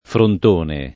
vai all'elenco alfabetico delle voci ingrandisci il carattere 100% rimpicciolisci il carattere stampa invia tramite posta elettronica codividi su Facebook frontone [ front 1 ne ] s. m. — sim. il pers. m. stor., top. e cogn.